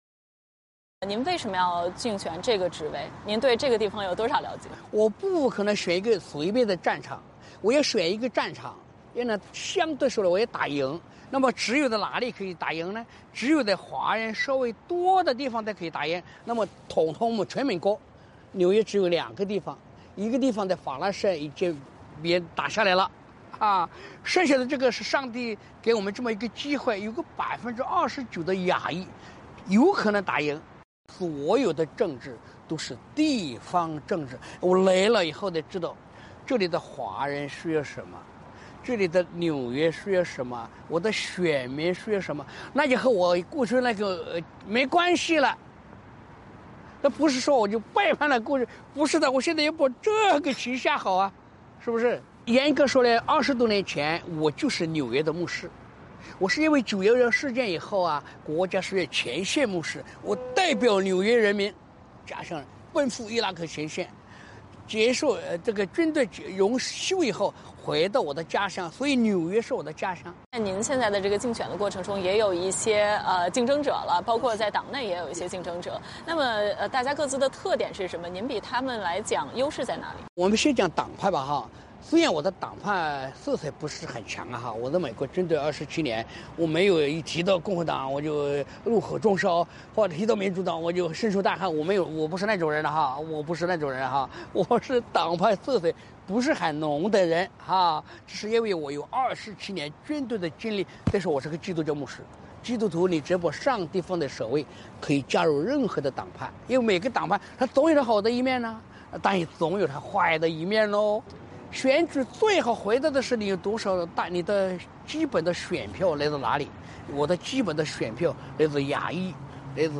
VOA专访: 前八九民运领袖熊焱谈参选国会议员